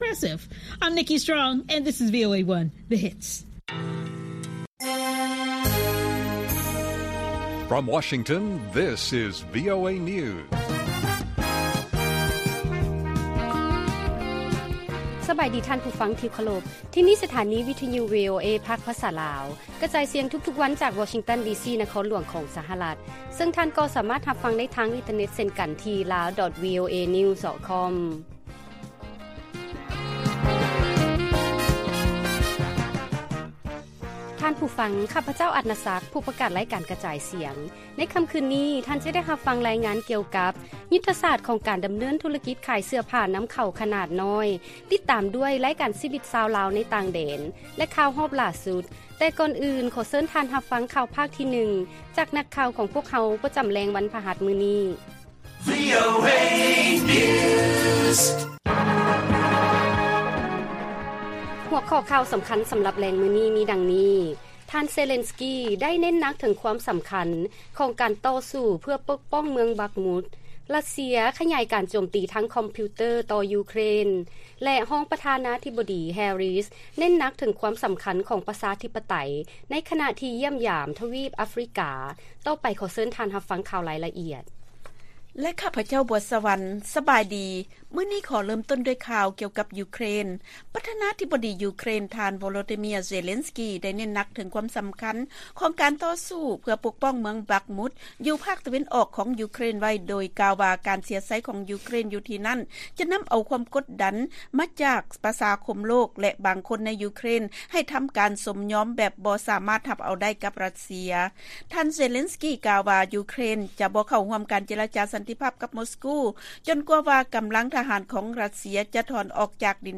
ລາຍການກະຈາຍສຽງຂອງວີໂອເອ ລາວ: ທ່ານເຊເລັນສ໌ກີ ໄດ້ເນັ້ນໜັກ ເຖິງຄວາມສຳຄັນຂອງການຕໍ່ສູ້ ເພື່ອປົກປ້ອງ ເມືອງບັກມຸດໄວ້